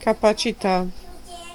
Ääntäminen
IPA : /kəˈpæs.ɪ.ti/